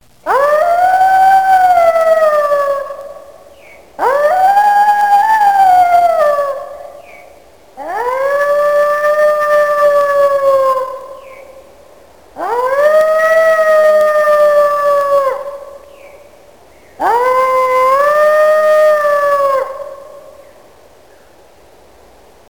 Вълк - Звуци от природата
• Категория: Вълци